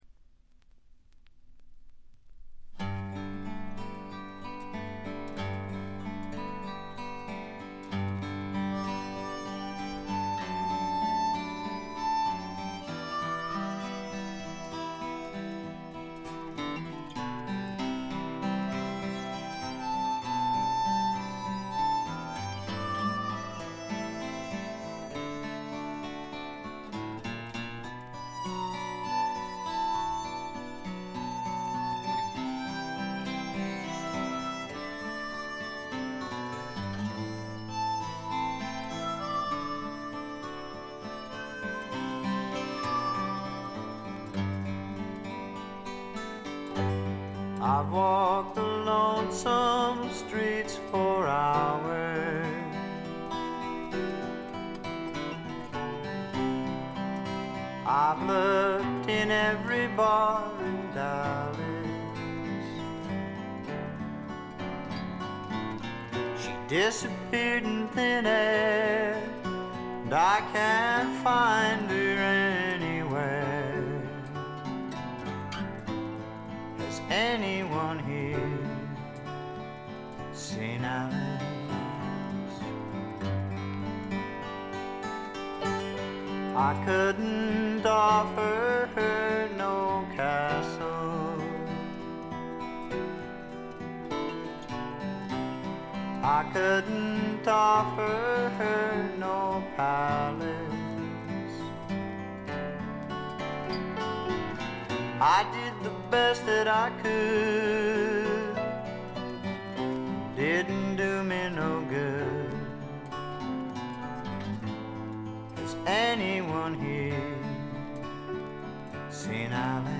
静音部で軽微なチリプチ少々。
試聴曲は現品からの取り込み音源です。